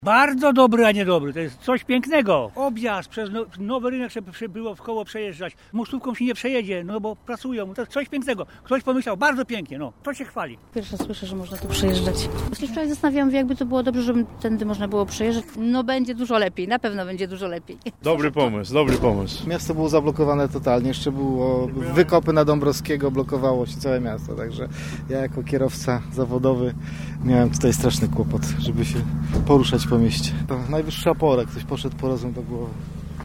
kierowcy-o-nowej-org-ruchu.mp3